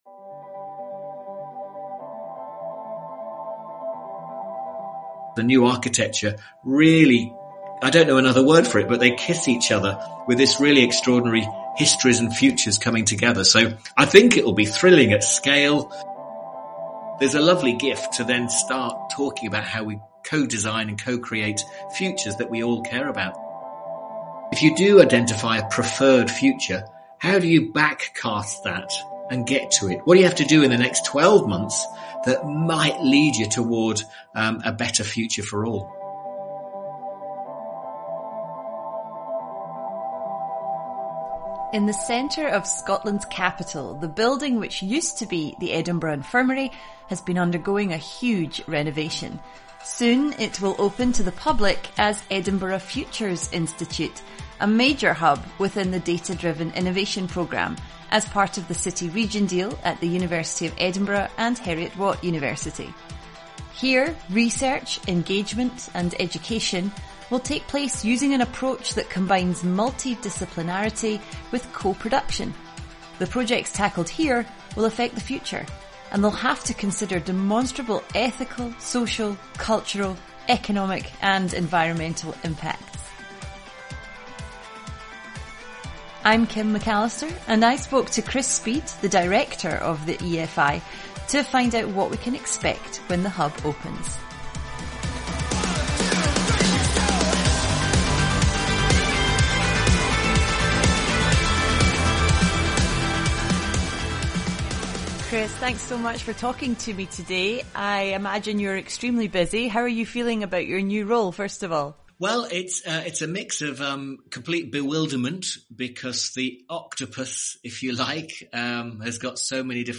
In this interview featured in Data-Driven Innovation’s 2022 Annual Review